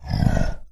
Monster_Hit5.wav